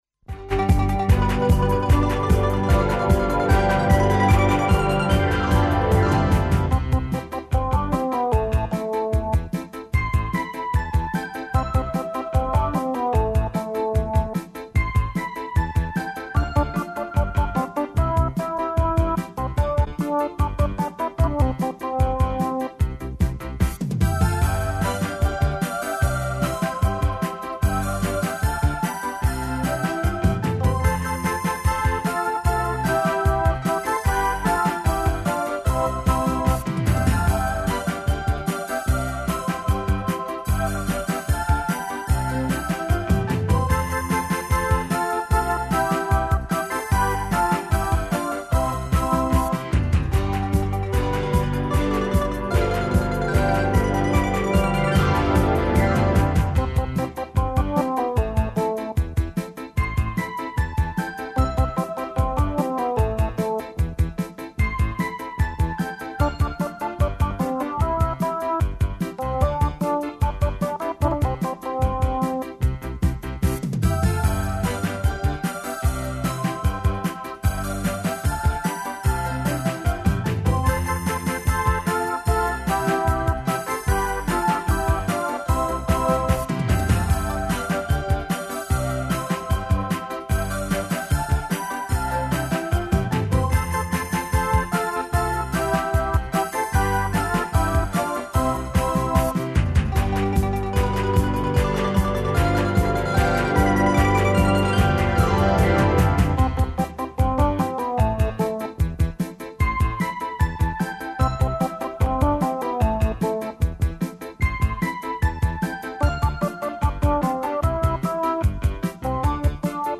Сваком детету су потребна правила која помажу да научи прикладно понашање. Како усадити деци правила и шта треба радити када су та правила прекршена, разговарамо данас са психологом и са децом који су наши гости.